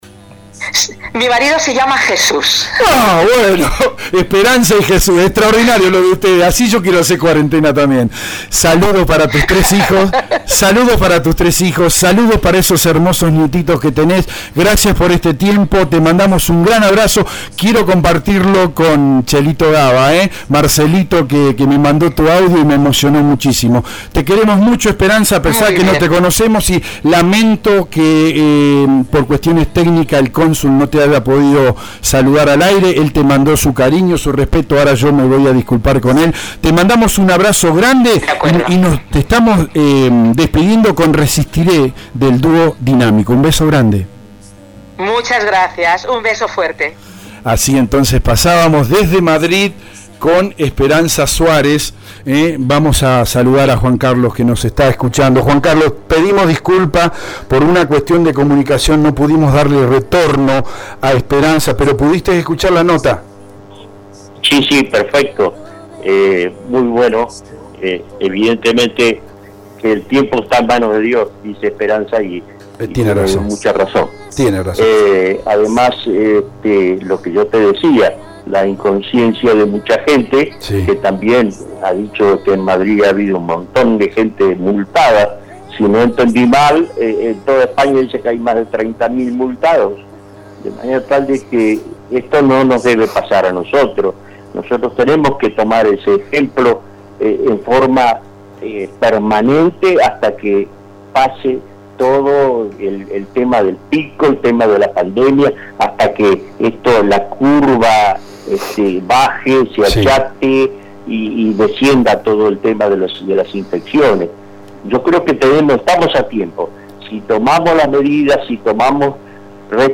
DESDE ESPAÑA: Hablamos con una locutora española sobre la crisis pandémica en su país “El ser humano es de corazón rebelde y no solidario” | EL DEBATE